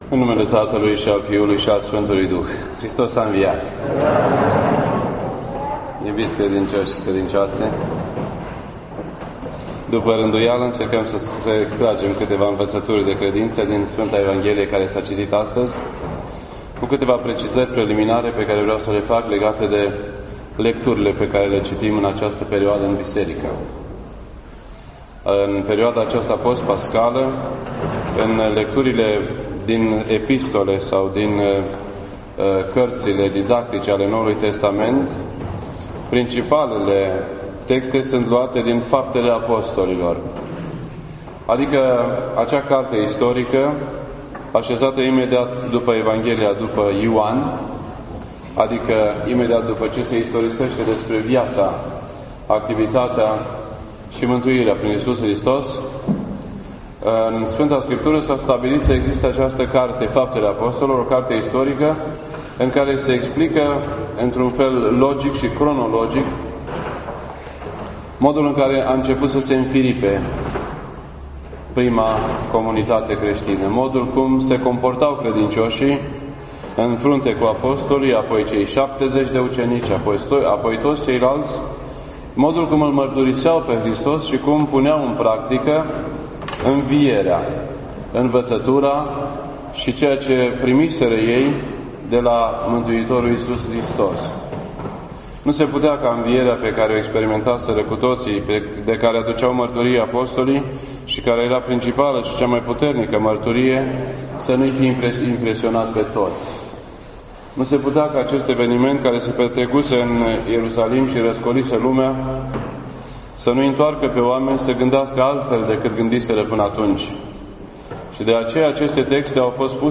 This entry was posted on Sunday, May 26th, 2013 at 8:45 PM and is filed under Predici ortodoxe in format audio.